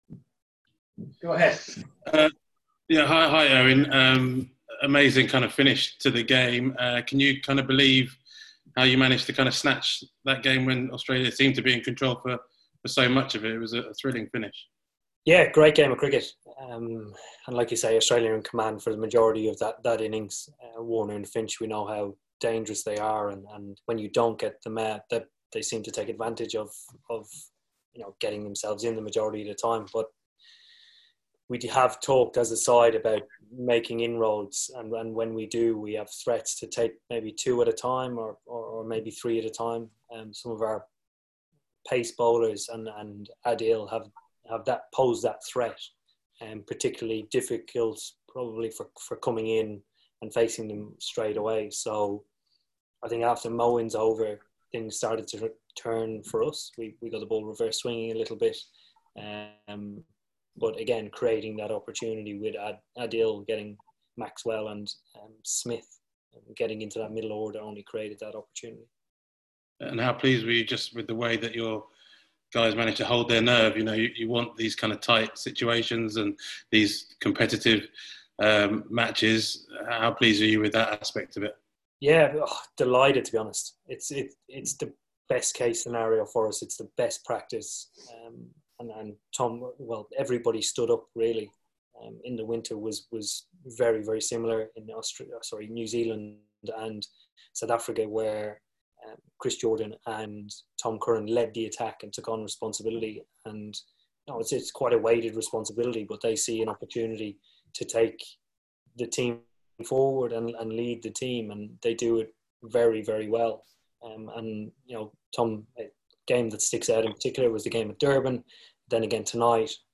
Eoin Morgan speaks after England’s victory over Australia in the first Vitality IT20
Eoin Morgan following England’s victory over Australia in the first Vitality IT20 at the Ageas Bowl.